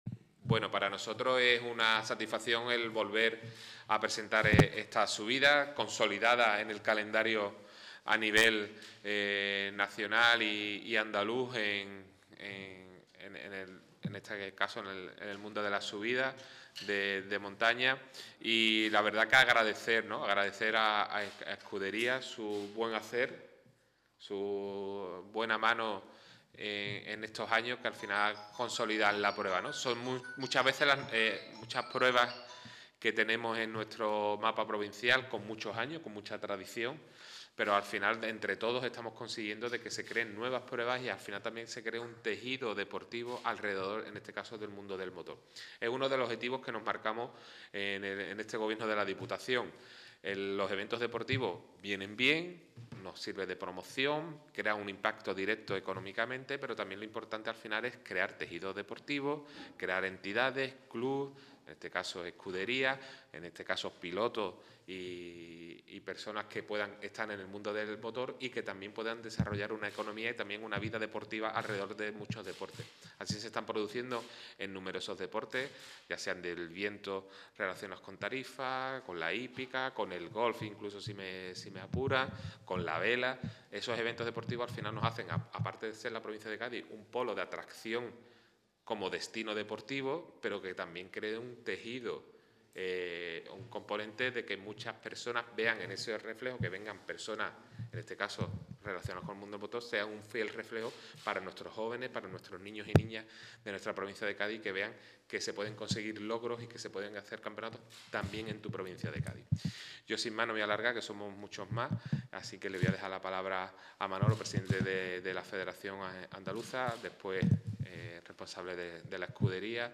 Audio completo de la rueda de prensa